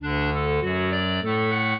clarinet
minuet0-12.wav